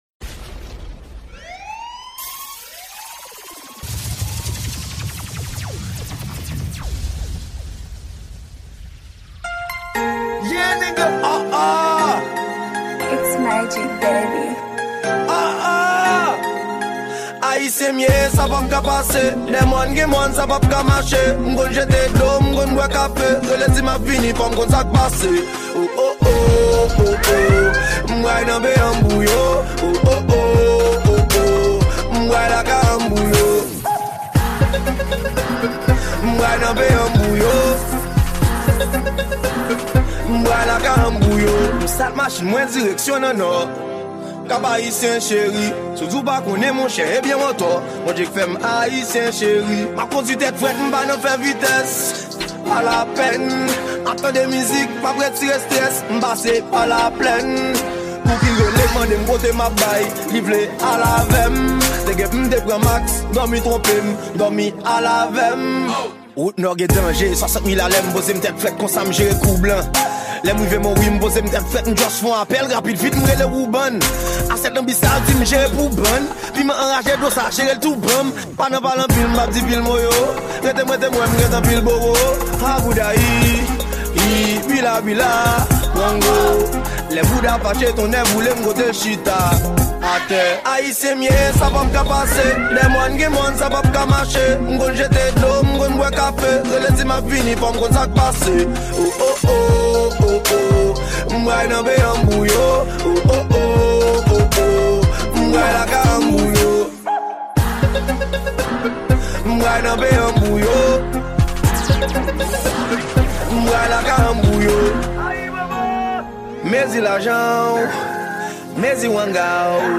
Genre : MXES